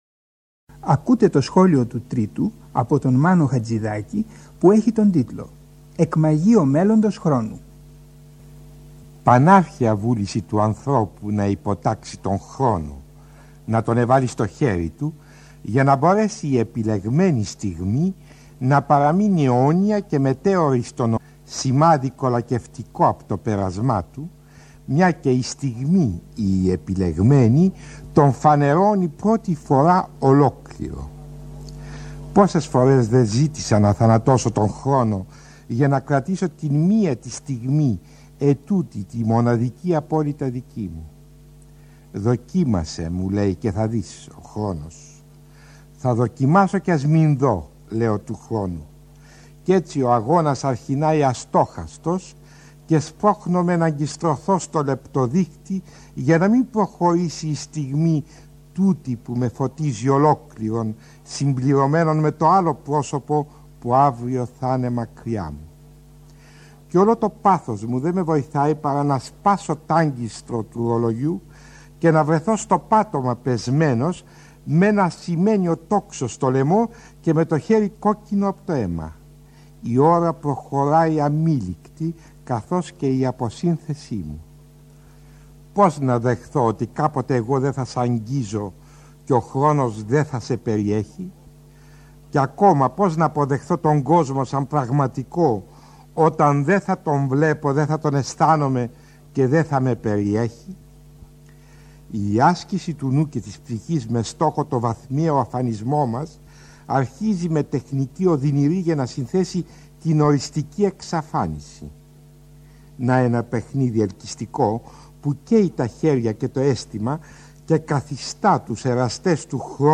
Σχόλιο του Τρίτου από το Μάνο Χατζιδάκι (Αρχείο Ελληνικής Ραδιοφωνίας)
Από το Αρχείο της Ελληνικής Ραδιοφωνίας, σχόλιο του Μάνου Χατζιδάκι, Το σχόλιο του Τρίτου: εκμαγείο Μέλλοντος χρόνου, που ακούστηκε για πρώτη φορά στις 30 Δεκεμβρίου 1979 και ξεκινά με τη φράση: Πανάρχαια βούληση του ανθρώπου, να υποτάξει τον Χρόνο, να τόνε βάλει στο χέρι του, για να μπορέσει η επιλεγμένη στιγμή, να παραμείνει αιώνια και μετέωρη στον ορίζοντα, σημάδι κολακευτικό από το πέρασμά του, μια και η στιγμή, η επιλεγμένη, τον φανερώνει, πρώτη φορά, ολόκληρο.